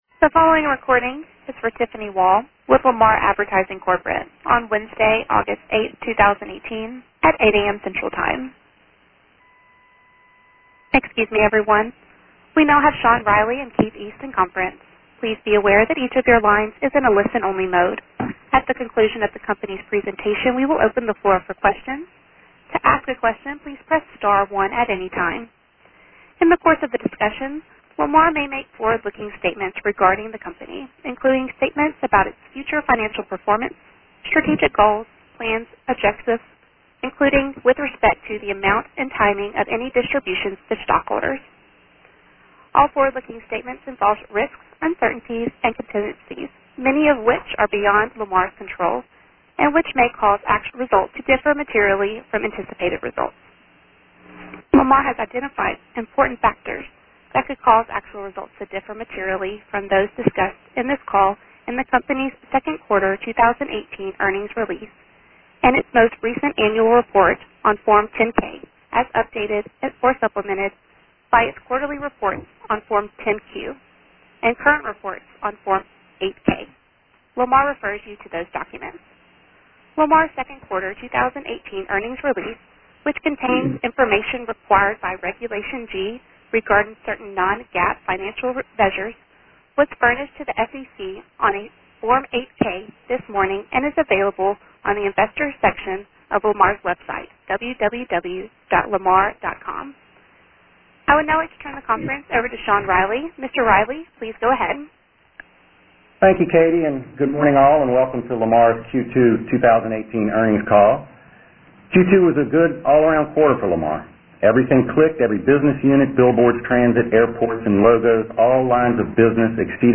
Lamar will host a conference call on Wednesday, August 8, 2018 at 8:00 a.m.